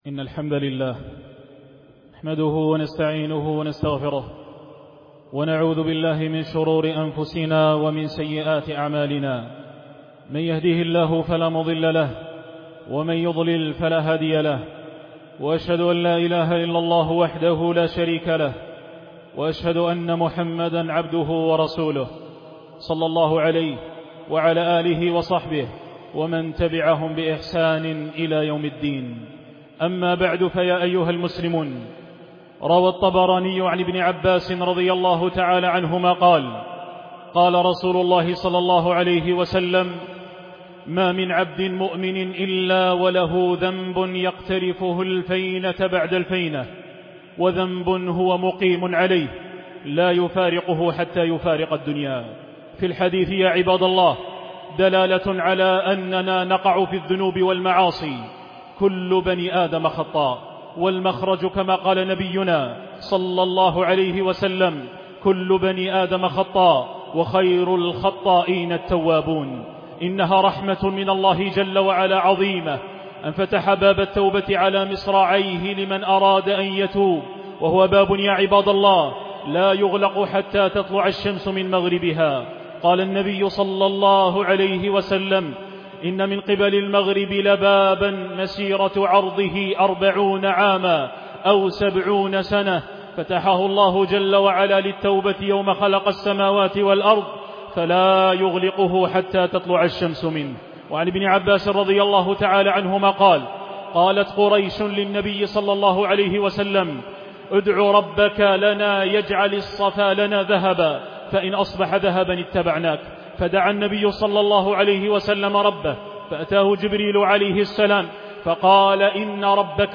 سماع الخطبة